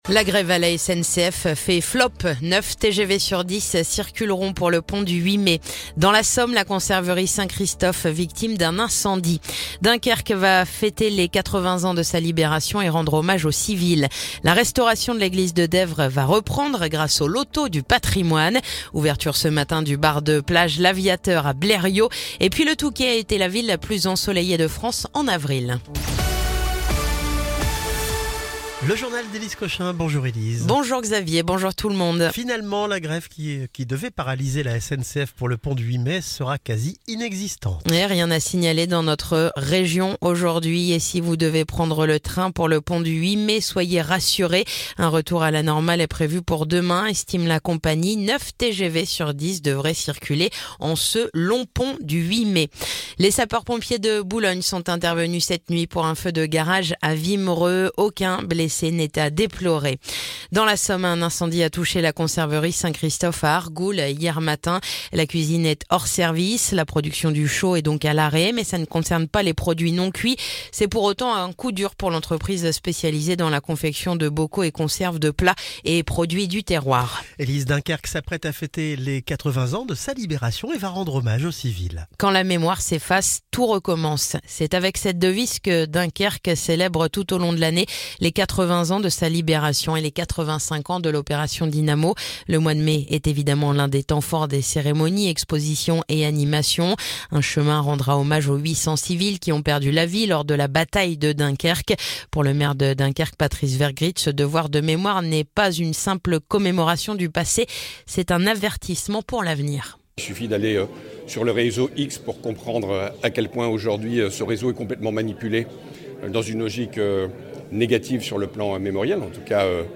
Le journal du mercredi 7 mai